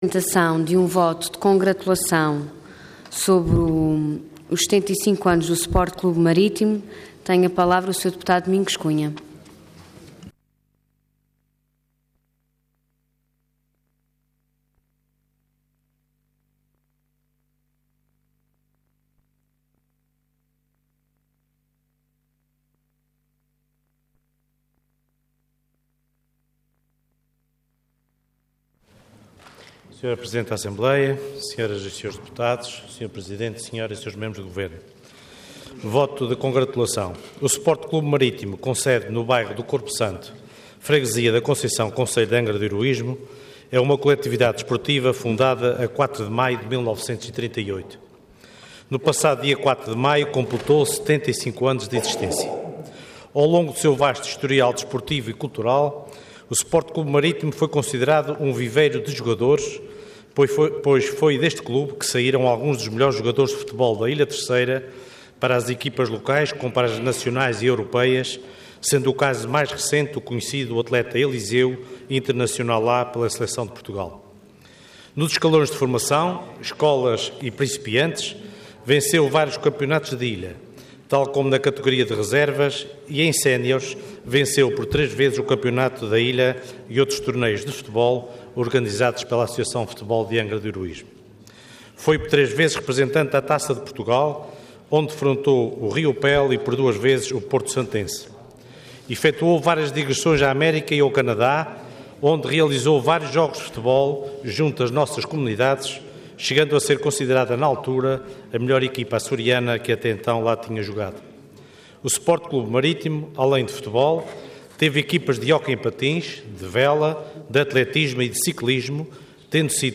Intervenção Voto de Congratulação Orador Domingos Cunha Cargo Deputado Entidade PS